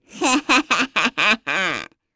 Pansirone-Vox_Happy2_kr.wav